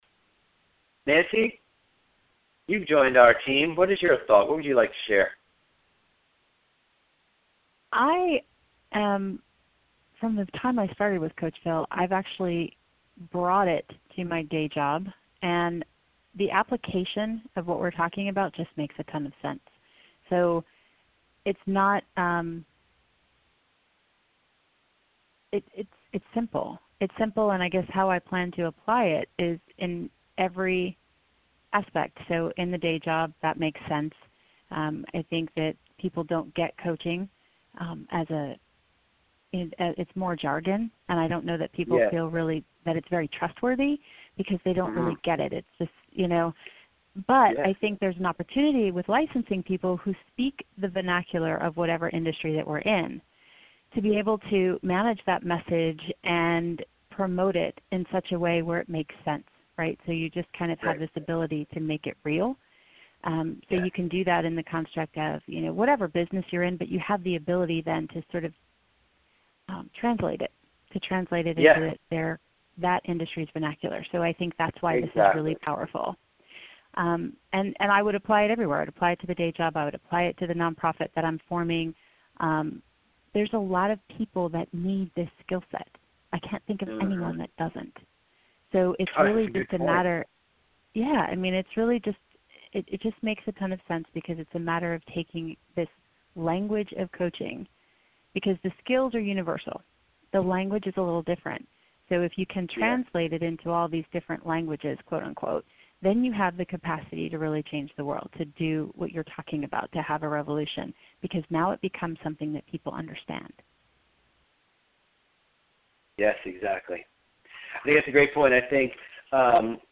CoachVille Certified Trainer Program Open House Call
I held an “Open House” conversation this week to share ideas and answer questions for coaches who were curious about the opportunity.
And several coaches who have already signed on to the TEAM attended as well to share their perspectives.